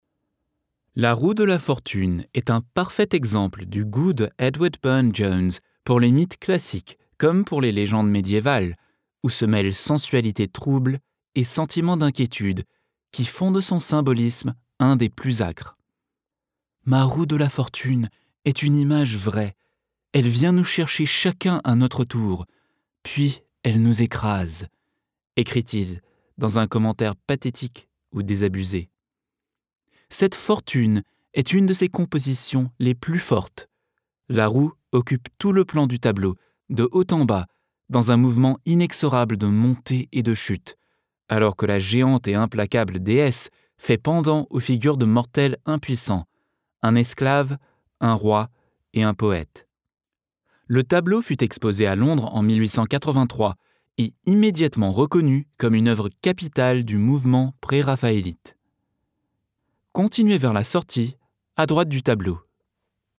Junge, Natürlich, Warm, Sanft, Corporate
Audioguide